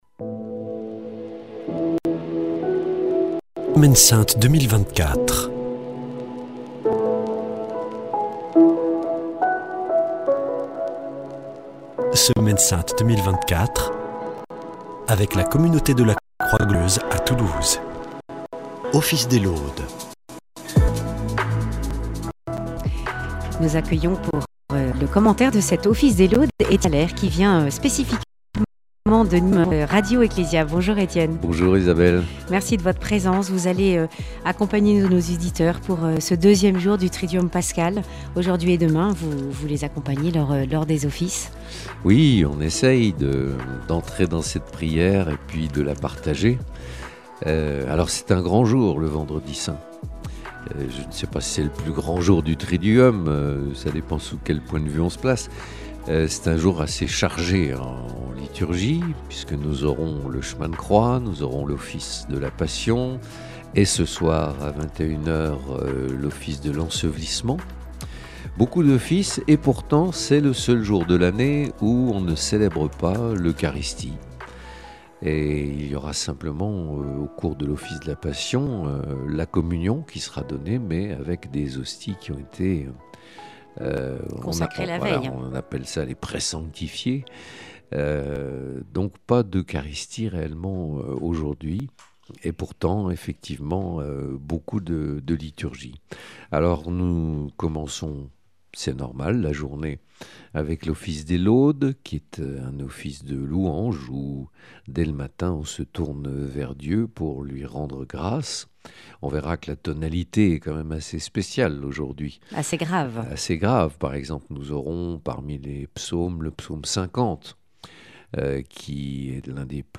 Les laudes du Vendredi Saint
Avec la communauté de la Croix Glorieuse, entrons dans la Passion du Christ.